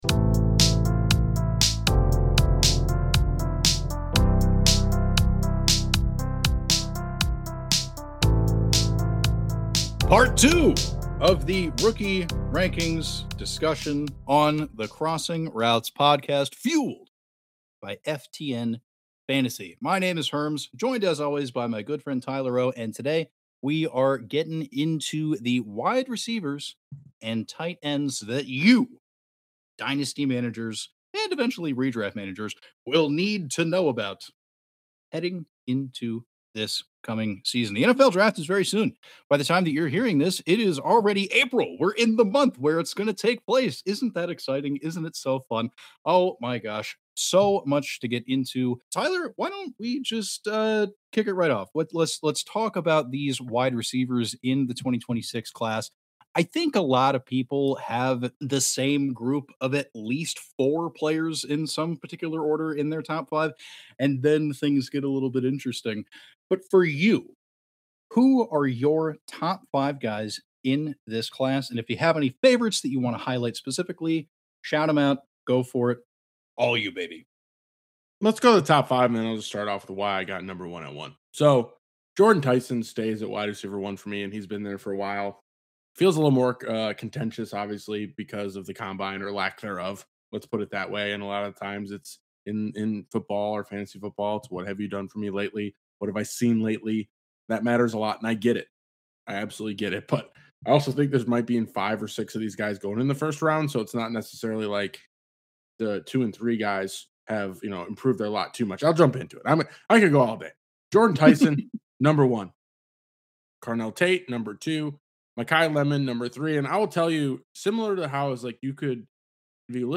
In this one, the duo continues their discussion of player rankings for the 2026 NFL Draft class. Which names appear in the top five at wide receiver and tight end?